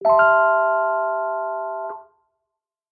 tere-n.mp3